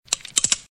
GUN COCKING THREE TIMES.mp3
Original creative-commons licensed sounds for DJ's and music producers, recorded with high quality studio microphones.
gun_cocking_three_times_ei8.ogg